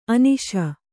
♪ aniśa